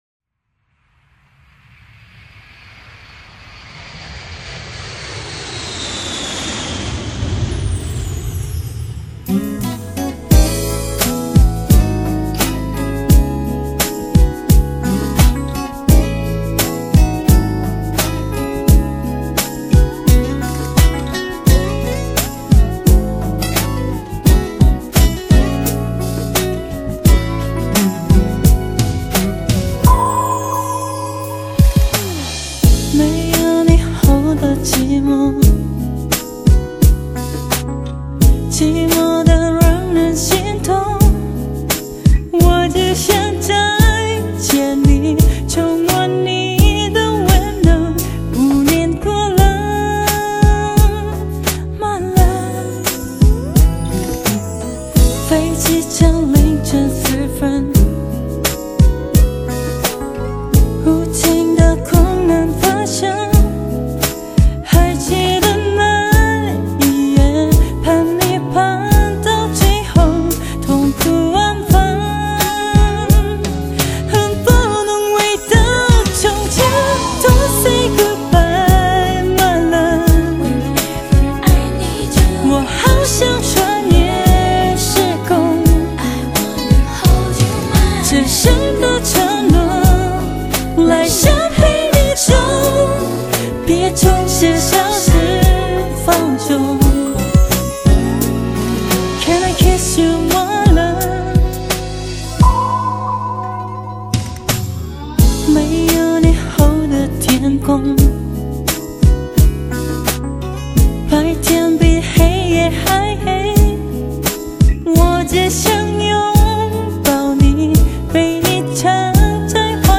专辑语种：国/韩语专辑1CD
音乐风格以纯种美式R&B嘻哈舞曲为主题。
整张专辑包含嬉哈、R＆B曲风。